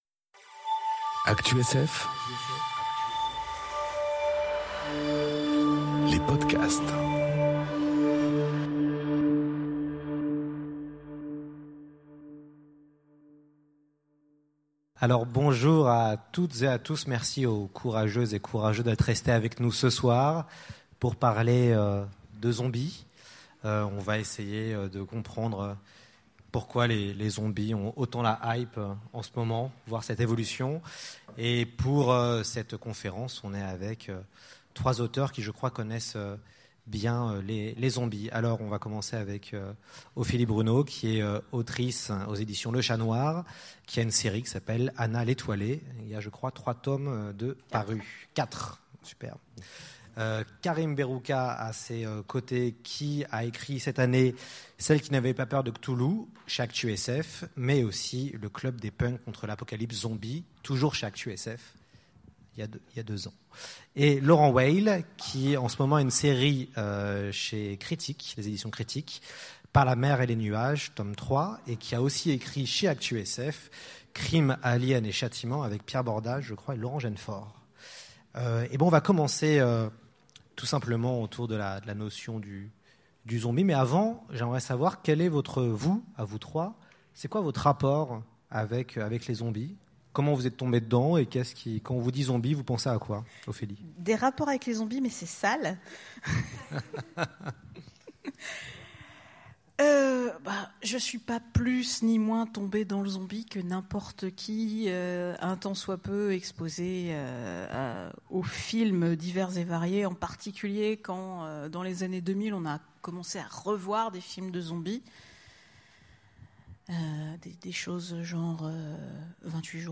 Conférence Shaun of the dead enregistrée aux Utopiales 2018